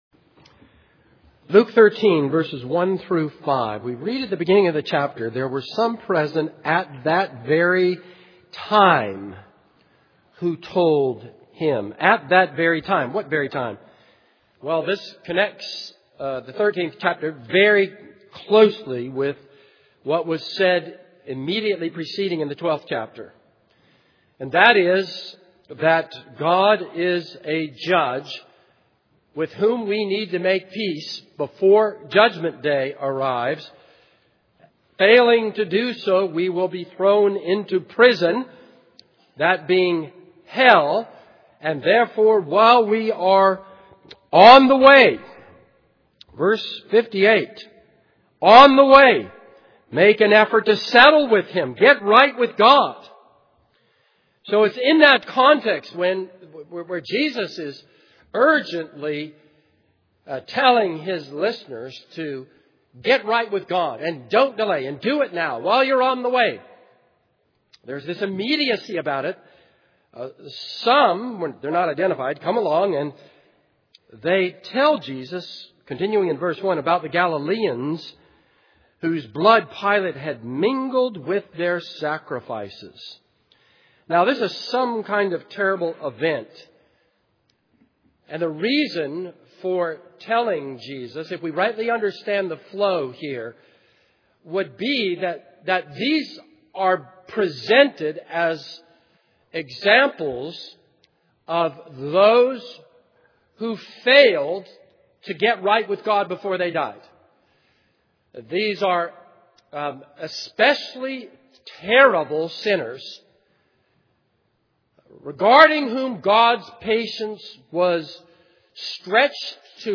This is a sermon on Luke 13:1-5.